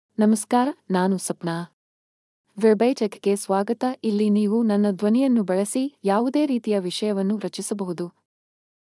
SapnaFemale Kannada AI voice
Sapna is a female AI voice for Kannada (India).
Voice sample
Female
Sapna delivers clear pronunciation with authentic India Kannada intonation, making your content sound professionally produced.